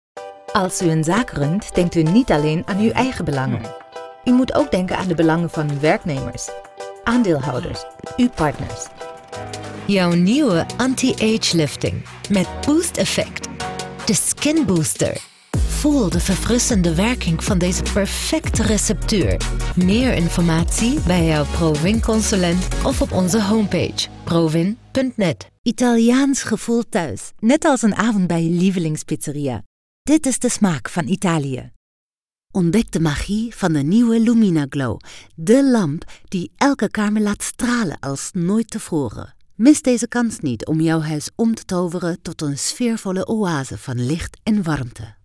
Female
Approachable, Bright, Character, Confident, Conversational, Corporate, Engaging, Friendly, Natural, Reassuring, Smooth, Versatile, Warm
Microphone: Rode NT1
Audio equipment: Focusrite Scarlett audio interface, pop filter, soundproof cabin